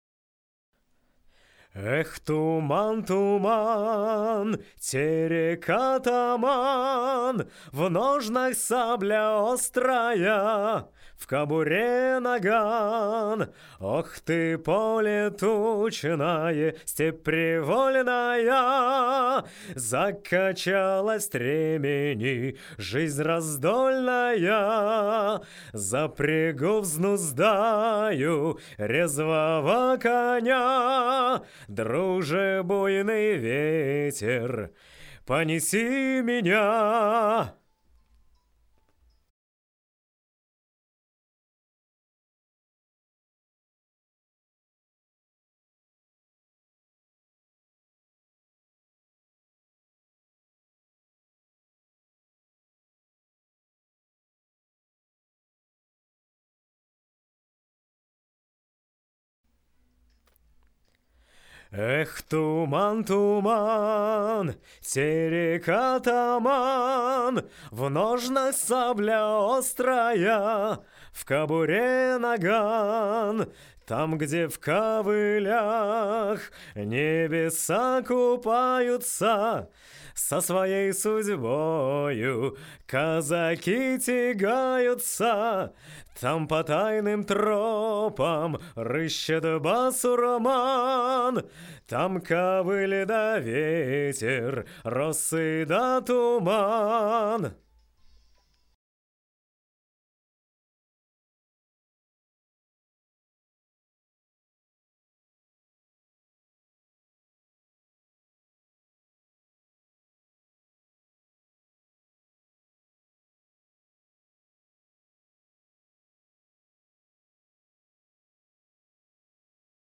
Вспомнил одну московскую студию, где записывали вокал.
В ней было всё по-минимуму, на мой дилетантский взгляд.
Прилагаю фото тон-зала и пример записанного в нем вокала. За красной занавеской окно в тихий дворик.